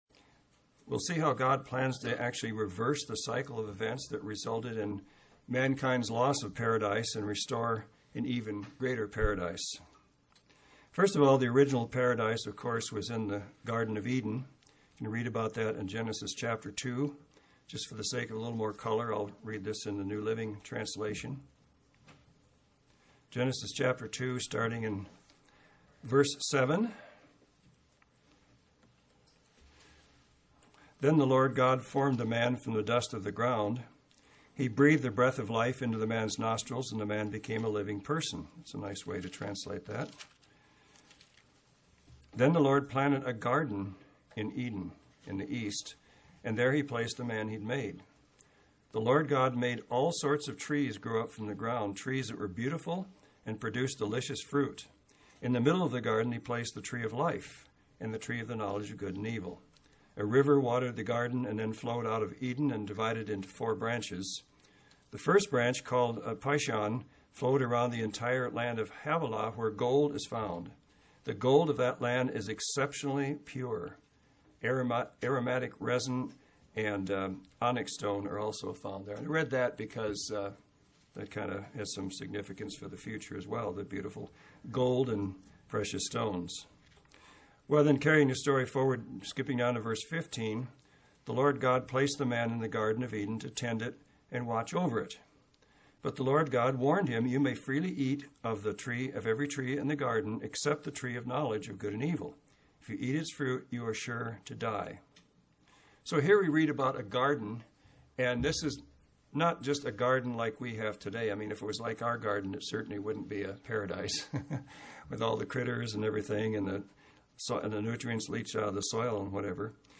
Introduction – As I mentioned in my Last Great Day message, this sermon will be a follow-up to that message.